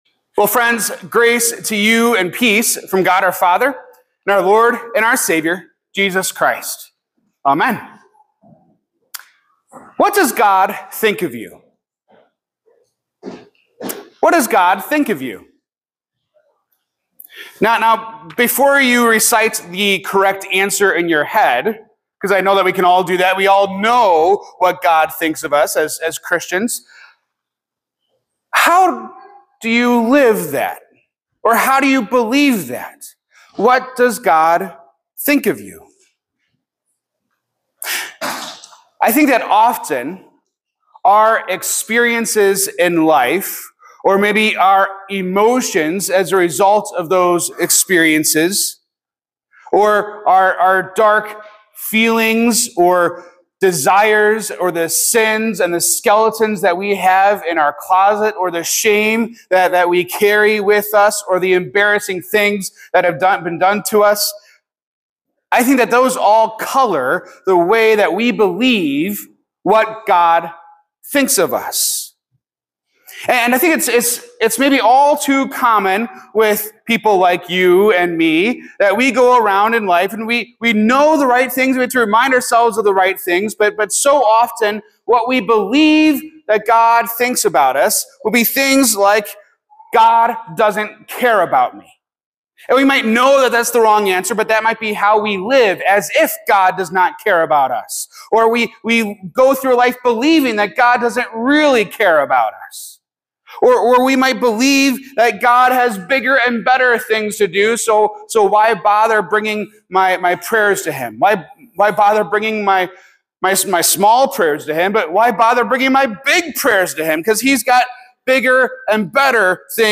This sermon explores how our perception of what God thinks of us profoundly impacts our faith. It highlights the challenge of truly believing the Gospel—that God loves and forgives us despite our shortcomings.